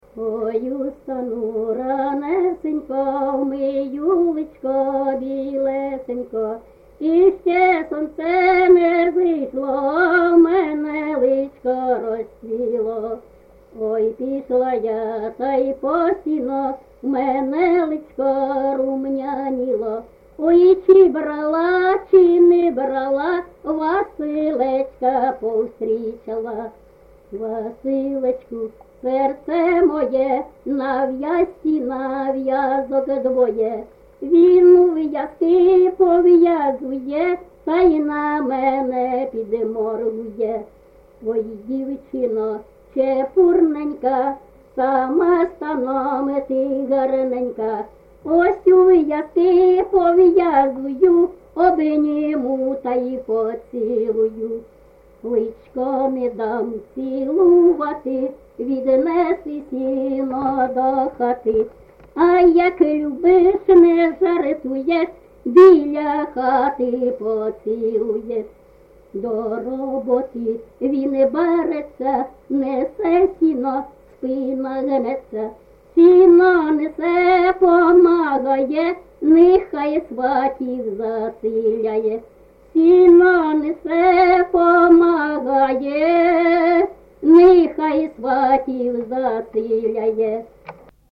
ЖанрПісні з особистого та родинного життя
Місце записус-ще Михайлівське, Сумський район, Сумська обл., Україна, Слобожанщина